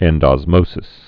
(ĕndŏz-mōsĭs, -dŏs-)